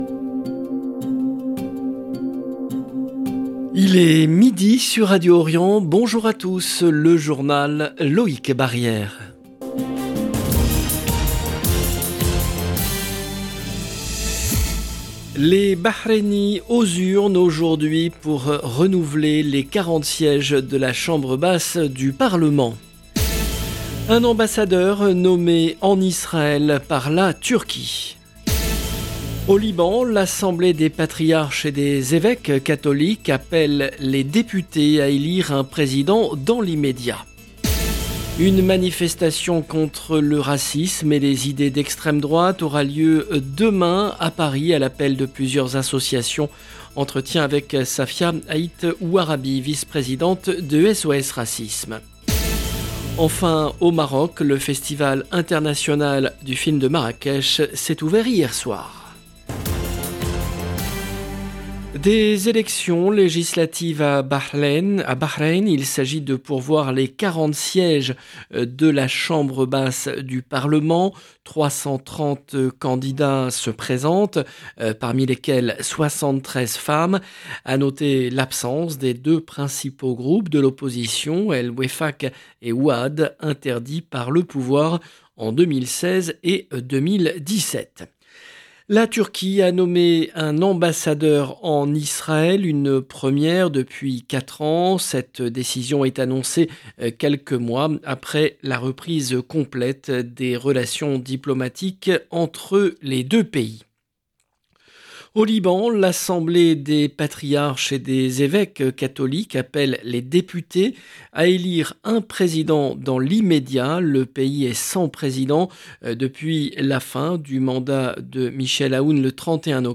LE JOURNAL EN LANGUE FRANCAISE DE MIDI DU 12/11/22